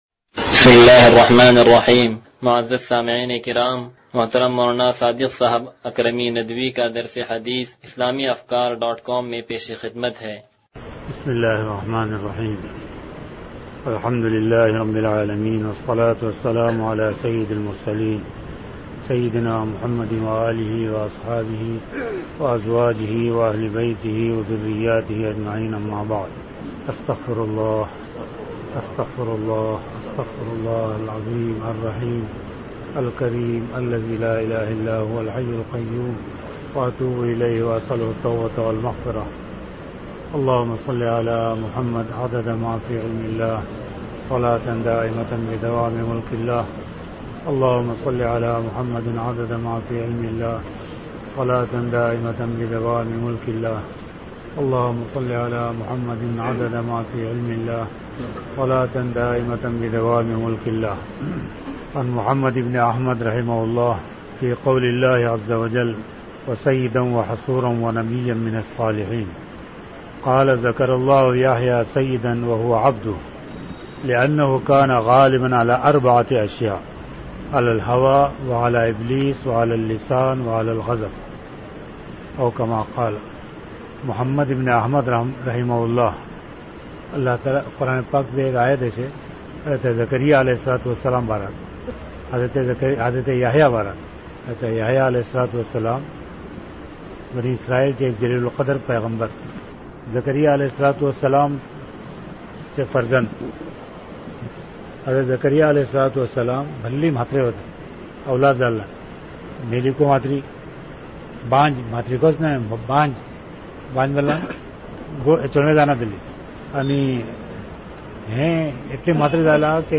درس حدیث نمبر 0084